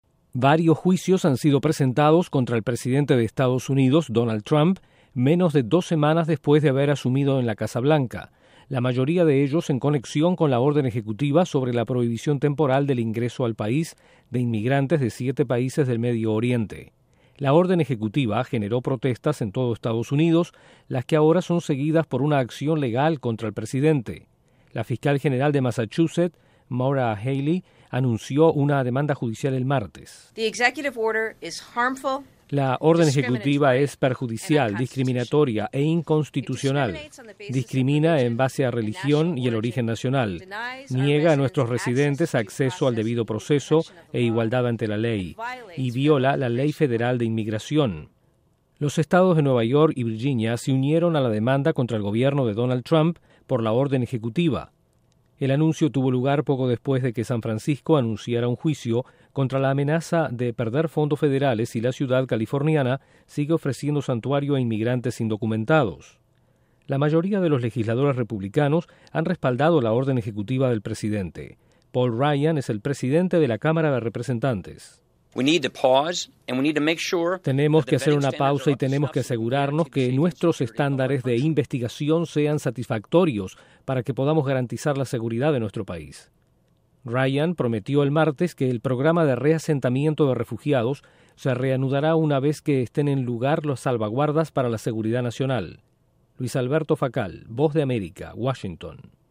El presidente de EE.UU. Donald Trump enfrenta varios juicios a menos de dos semanas en la Casa Blanca. Desde la Voz de América en Washington informa